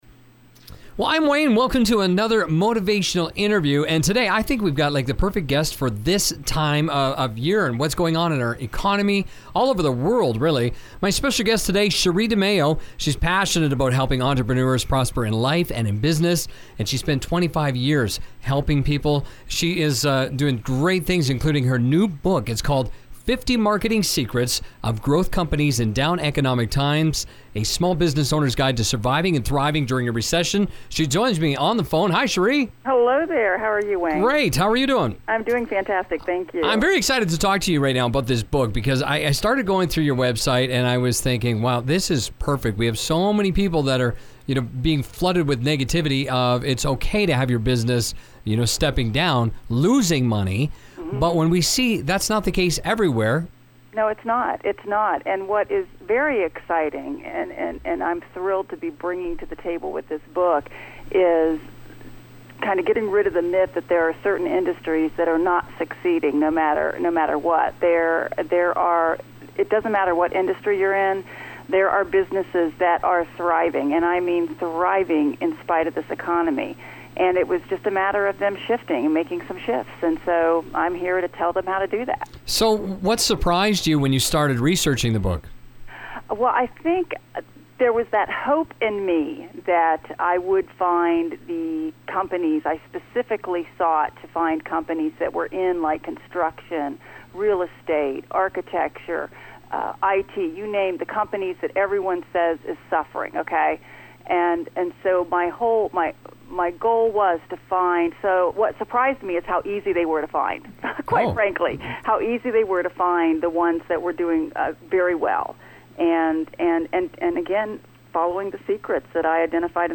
50 Marketing Secrets – Motivational Interview